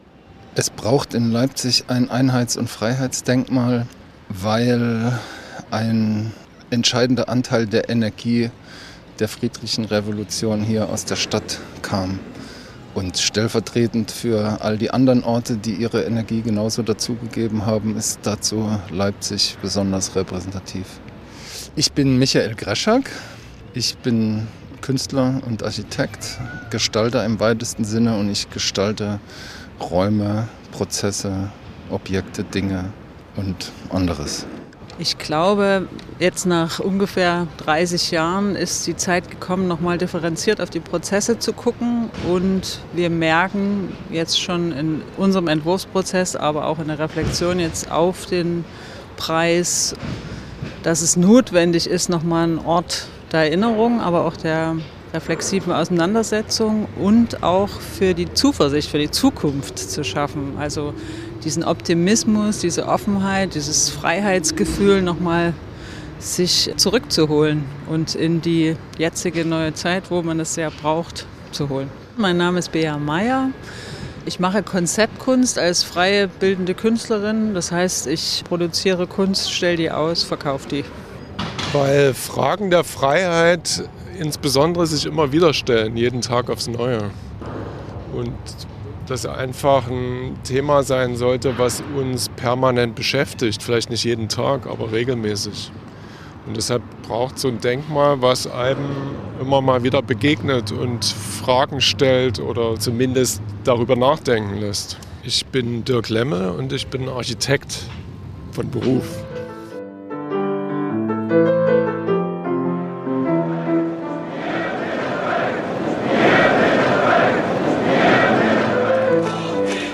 Wir sitzen auf dem Wilhelm-Leuschner-Platz - mitten in der Stadt, direkt neben dem Innenstadtring, wo am 9. Oktober 1989 rund 70.000 Menschen demonstriert haben.